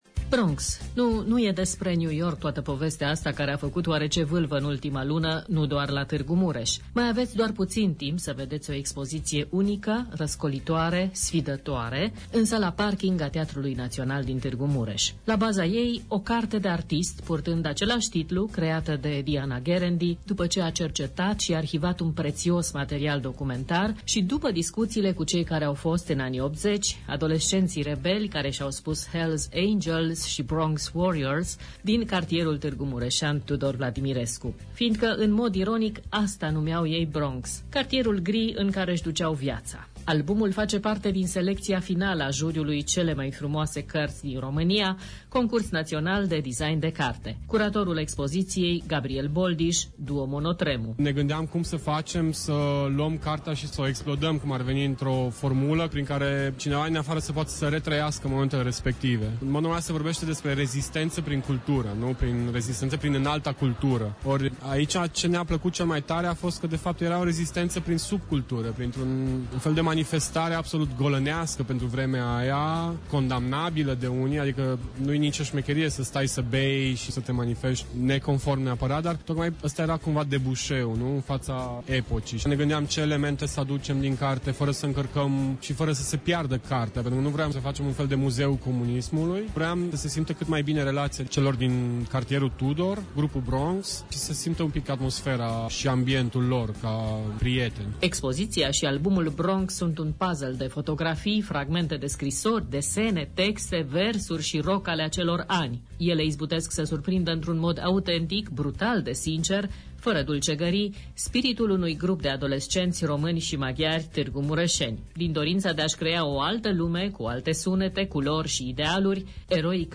28-NOV-REPOTAJ-BRONX-1.mp3